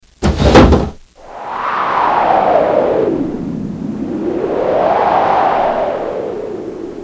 Tiếng mở Cửa Sổ và tiếng Gió thổi bên ngoài
Thể loại: Tiếng động
Description: Hiệu ứng âm thanh bắt đầu bằng âm thanh “cạch” hoặc “kẽo kẹt” khi cánh cửa sổ bật ra, ngay lập tức tiếp nối bởi tiếng gió “vù vù”, “ù ù” hoặc “ào ào” ùa vào. Sự kết hợp này tạo cảm giác trống trải, lạnh lẽo và bất ngờ.
Tieng-mo-cua-so-va-tieng-gio-thoi-ben-ngoai-www_tiengdong_com.mp3